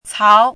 chinese-voice - 汉字语音库
cao2.mp3